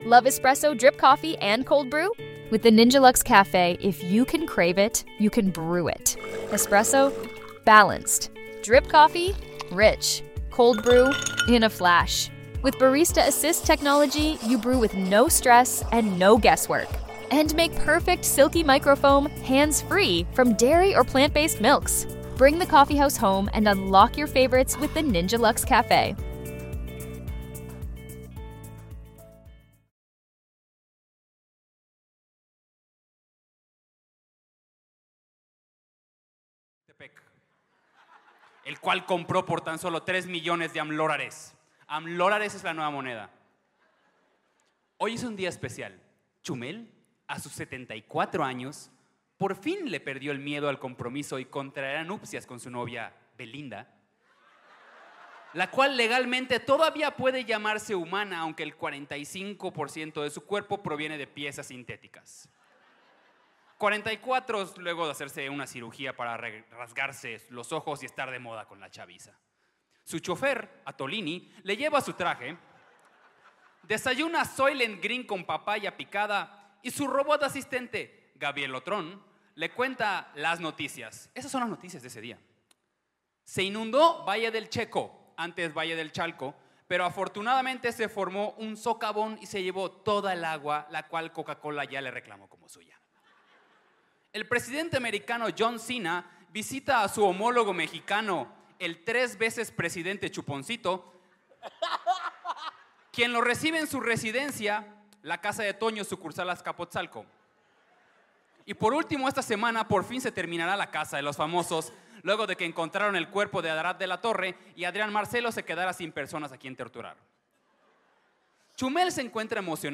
EN VIVO DESDE CELAYA